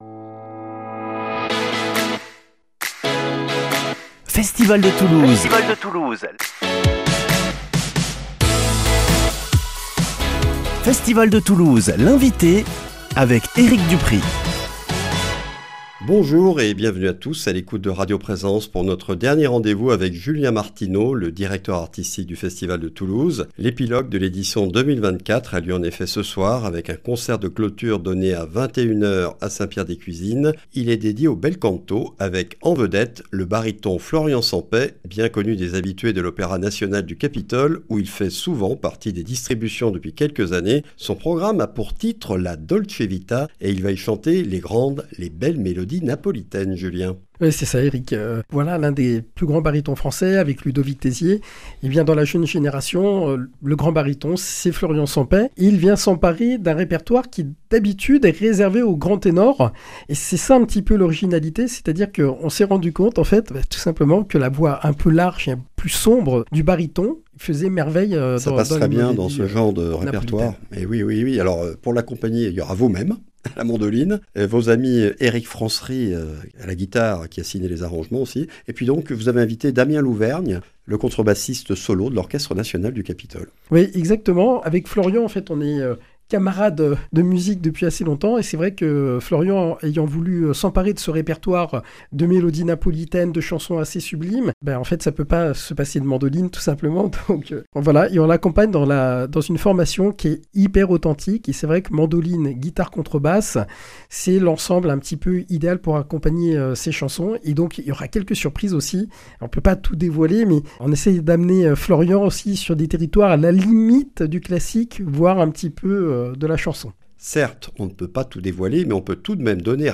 samedi 13 juillet 2024 Festival de Toulouse - Interviews Durée 12 min